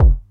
drum37.mp3